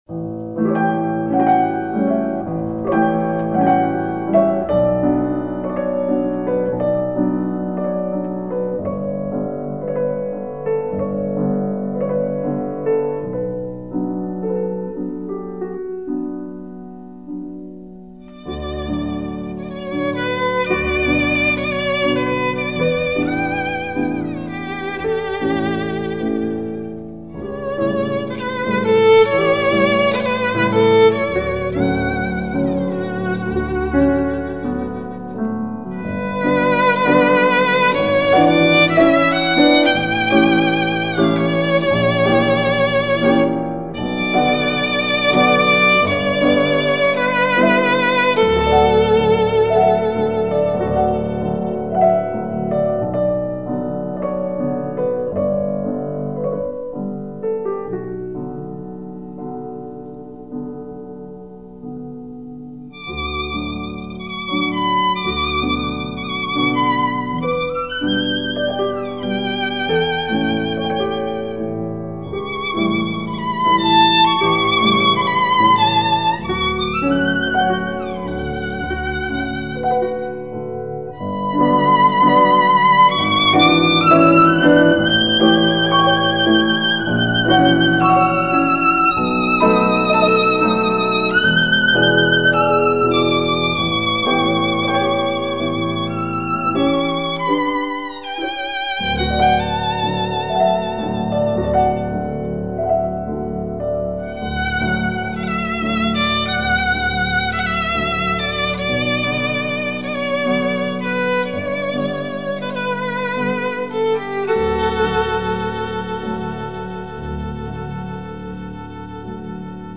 温暖亲切，无比慰藉，颂扬母爱的千古绝唱